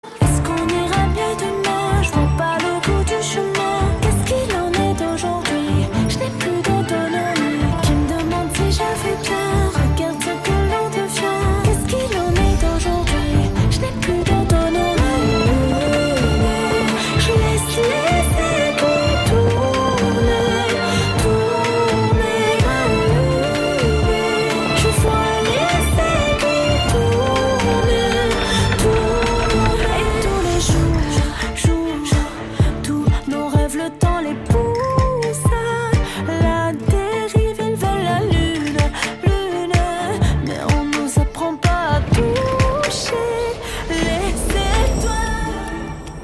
Categoria Rap